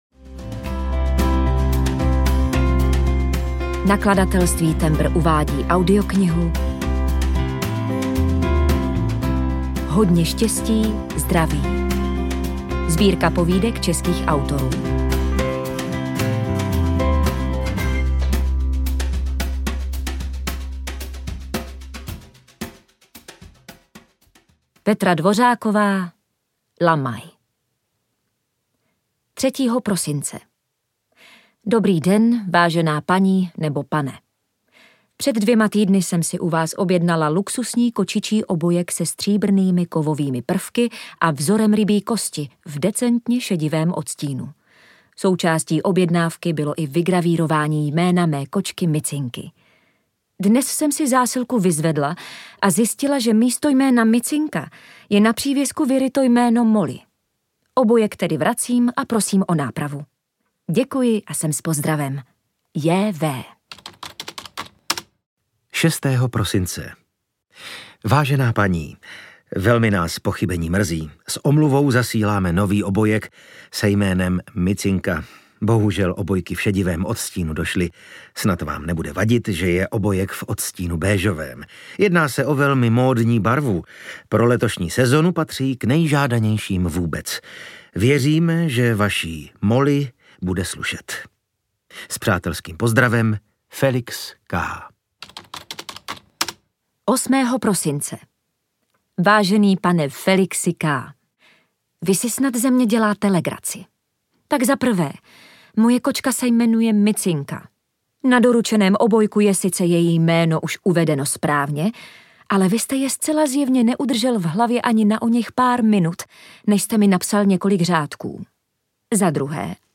HODNĚ ŠTĚSTÍ, ZDRAVÍ… audiokniha
Ukázka z knihy
hodne-stesti-zdravi-audiokniha